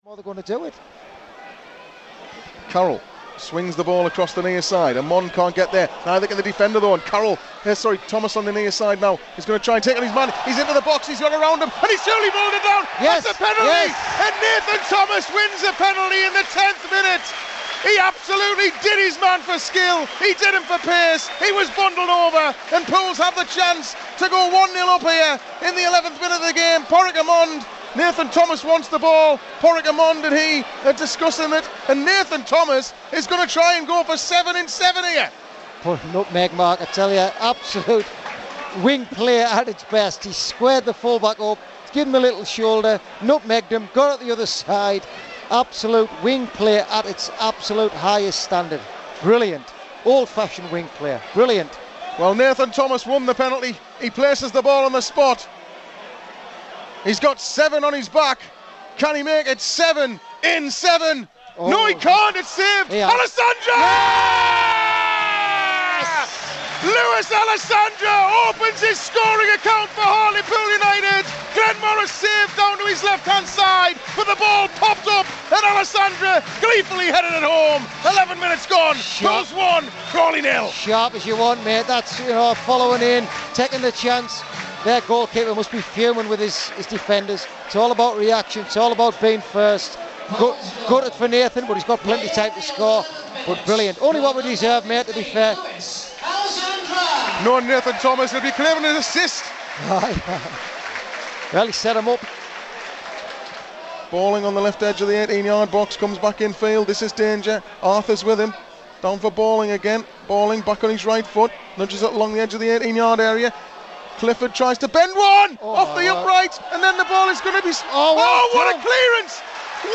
Listen back to how the key moments from Saturday's draw with Crawley Town sounded live on Pools PlayerHD.